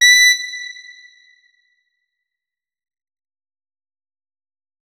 4aef571f59 Divergent / mods / Hideout Furniture / gamedata / sounds / interface / keyboard / saxophone / notes-71.ogg 35 KiB (Stored with Git LFS) Raw History